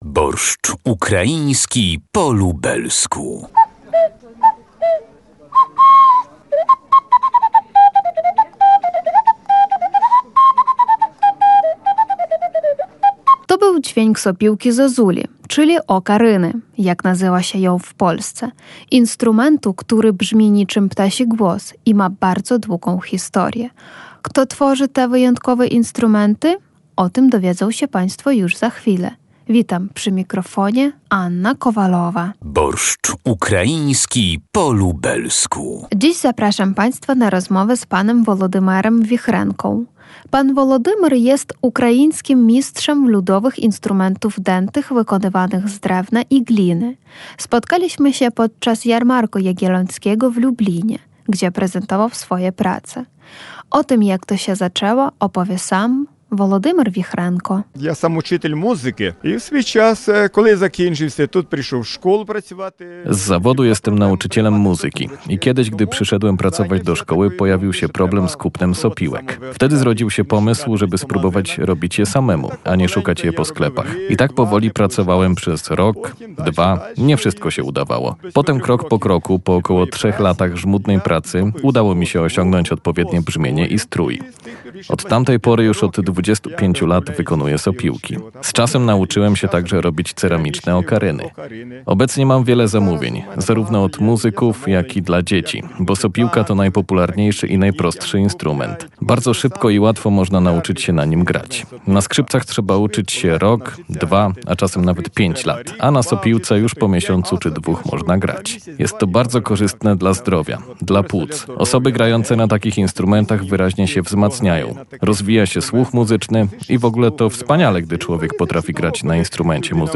To był dźwięk sopiłki-zozuli, czyli okaryny, jak nazywa się ją w Polsce. Instrumentu, który brzmi niczym ptasi głos i ma bardzo długą historię. Kto tworzy te wyjątkowe instrumenty?